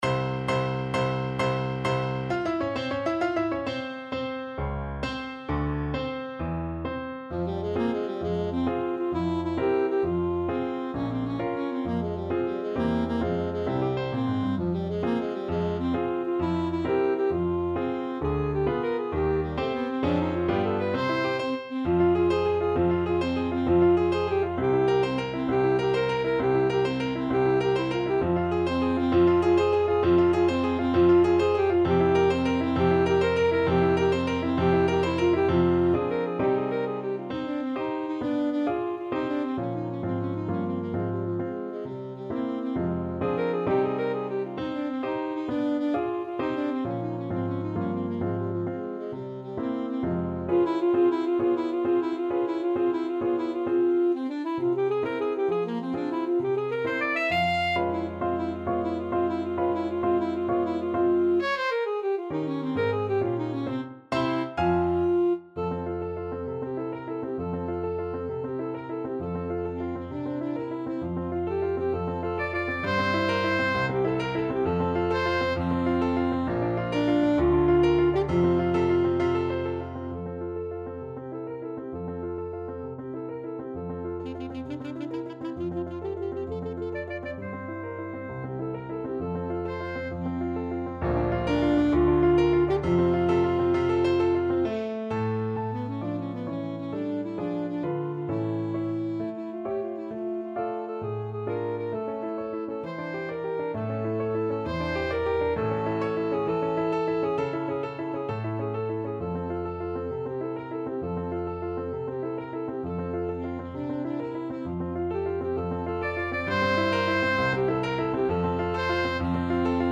Free Sheet music for Alto Saxophone
6/8 (View more 6/8 Music)
Classical (View more Classical Saxophone Music)